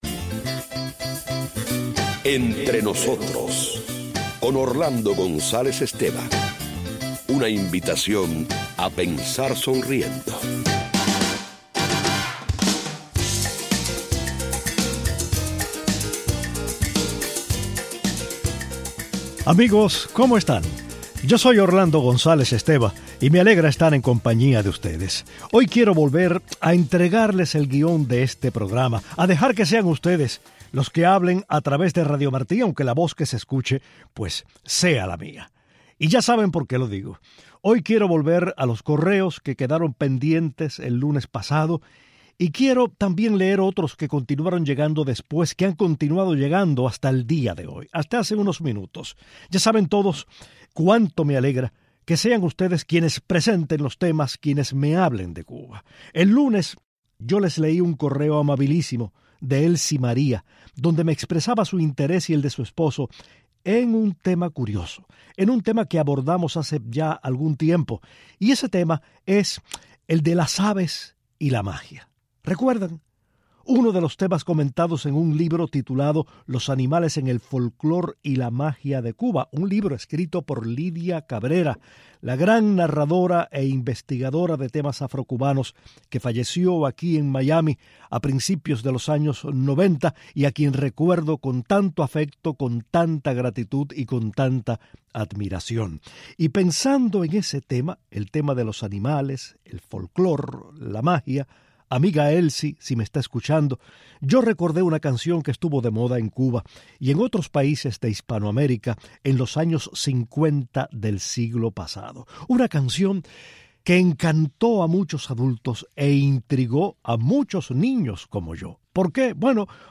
Los radioescuchas hacen el programa. Uno compara a la isla con la ceiba de su patio, azotada por el huracán.